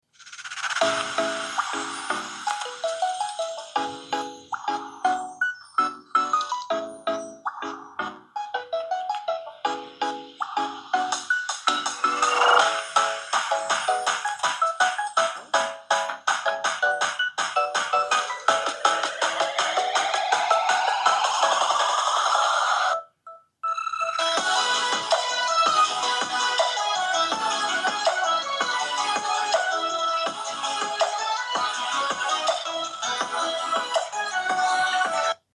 Redmi Pad 2 シリーズ の音質【クアッドスピーカー＋Dolby Atmos】
スピーカー ステレオスピーカー（クアッド構成）
対応規格 ハイレゾ、Dolby Atmos